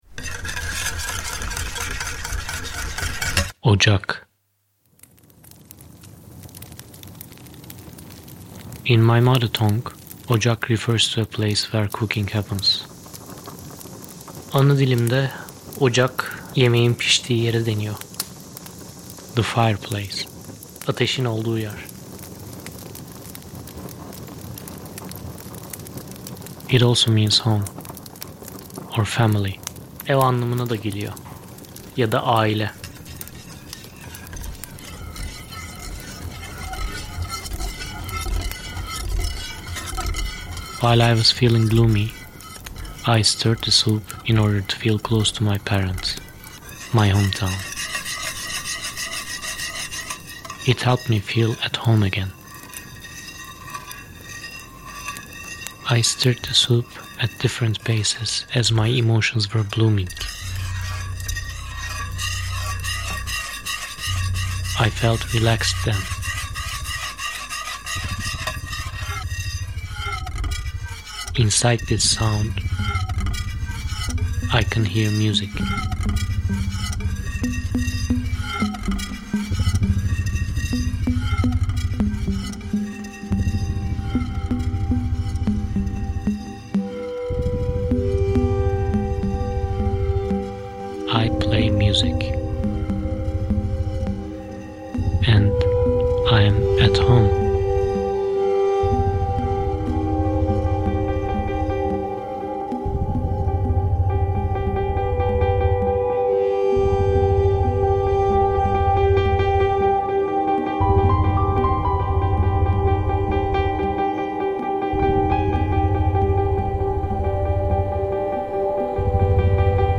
I used some of my own soundscapes (recorded in Spain, Portugal and France, places where I lived) and some piano music I improvised while editing."